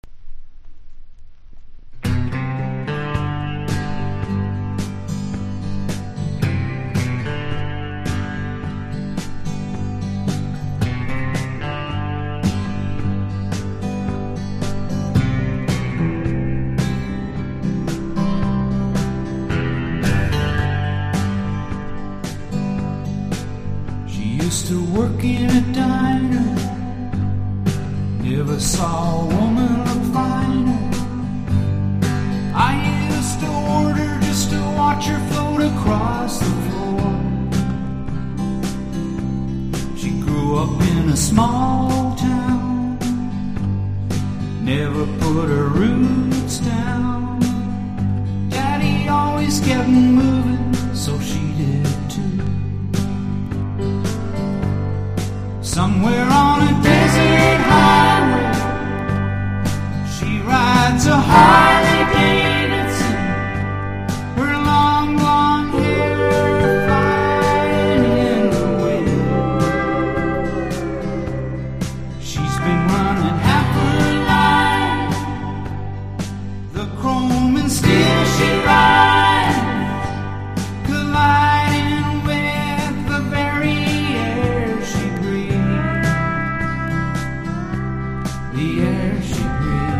1. 70'S ROCK >
SSW / FOLK